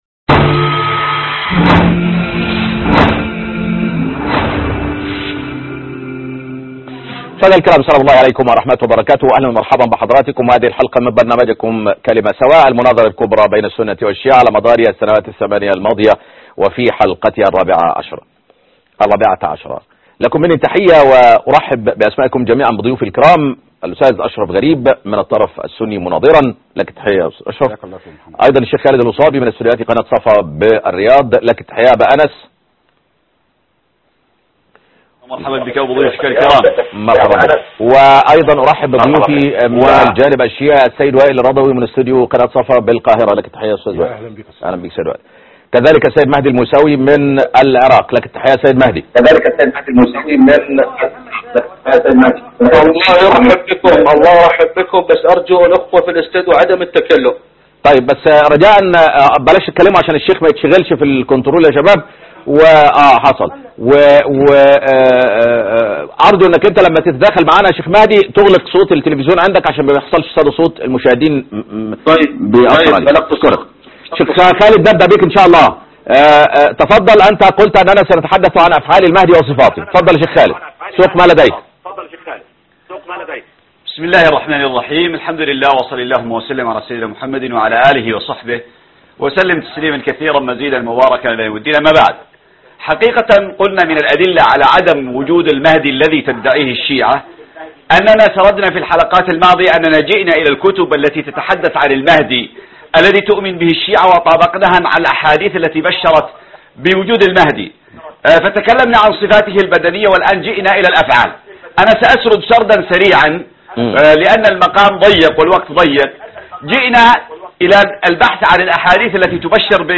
مناظرة حول أفعال المهدى وصفاته(19/6/2016) كلمة سواء